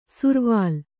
♪ survāl